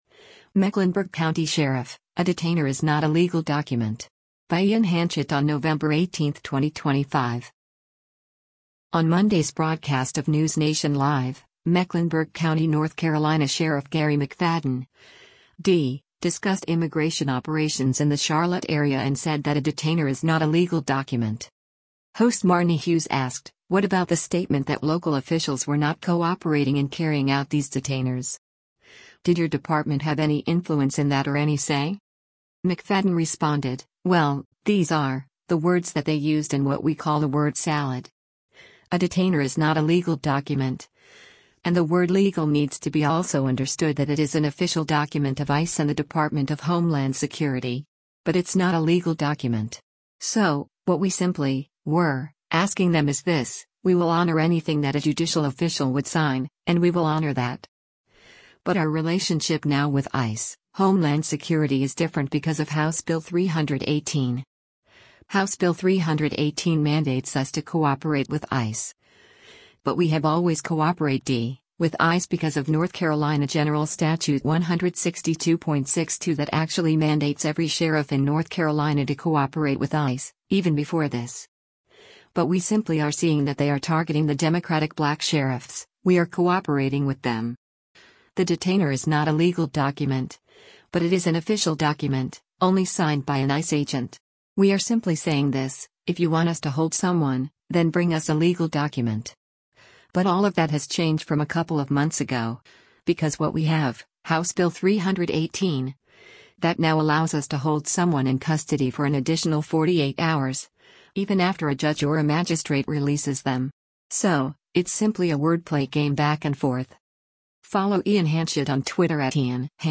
On Monday’s broadcast of “NewsNation Live,” Mecklenburg County, NC Sheriff Garry McFadden (D) discussed immigration operations in the Charlotte area and said that a detainer is “not a legal document.”